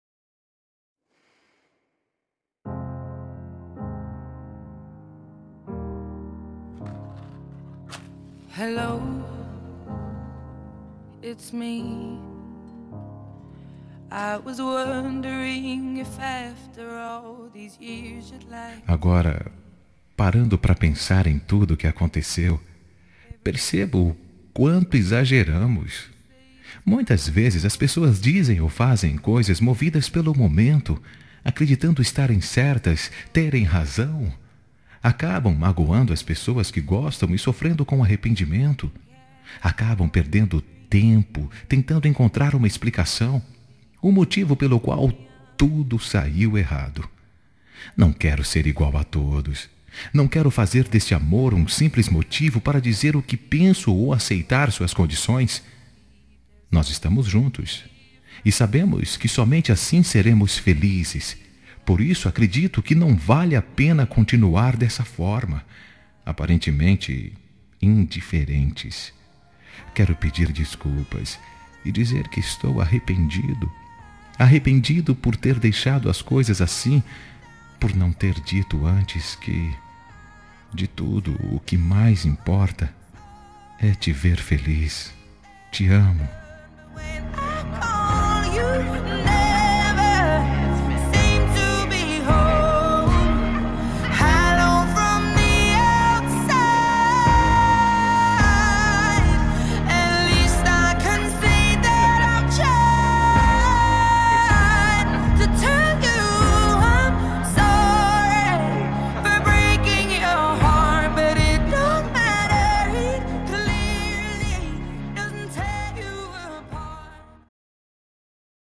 Telemensagem de Desculpas – Voz Masculina – Cód: 393